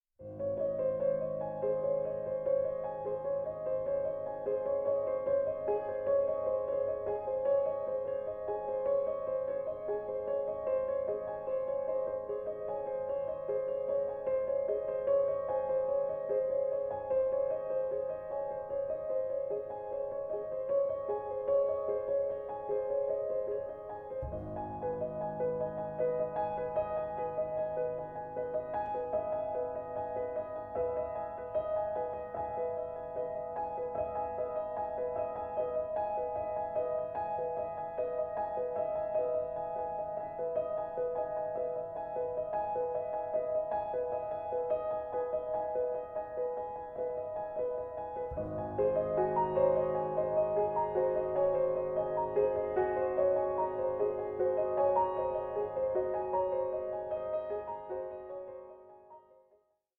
organist and keyboard player